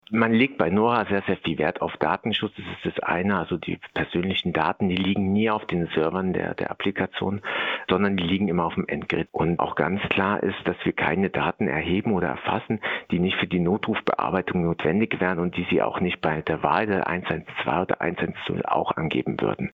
Interview - Nora App - PRIMATON